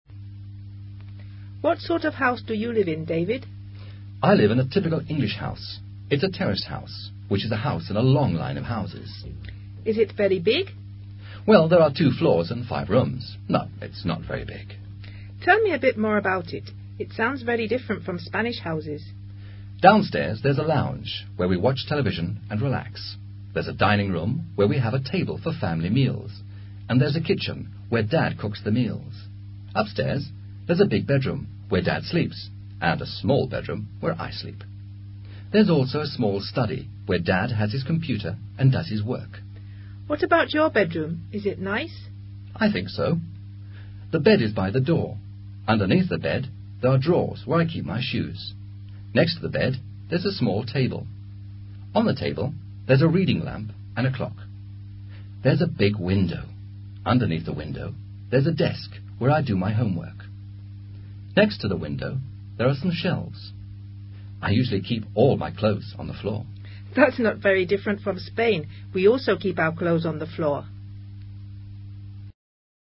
Un joven describe su casa y su pieza.